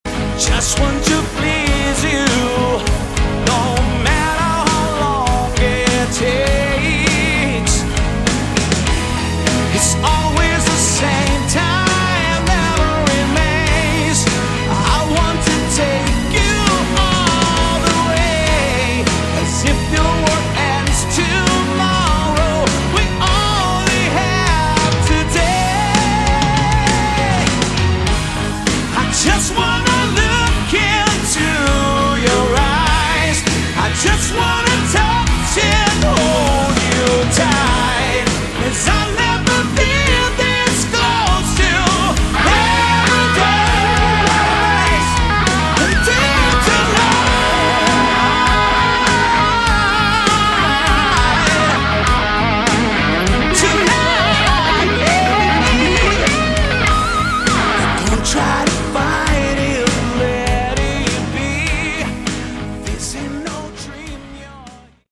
Category: Melodic Hard Rock
Vocals
Guitars
Drums
Bass
Backing vocals